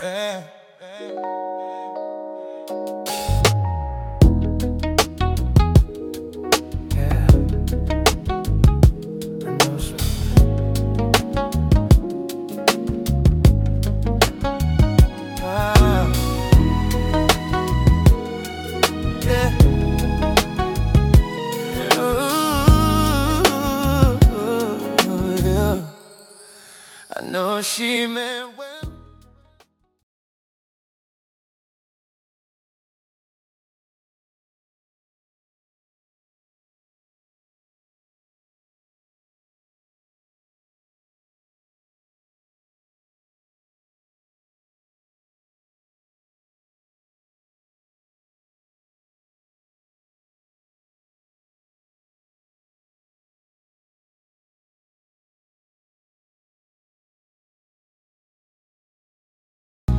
🎧 Genre:  Soul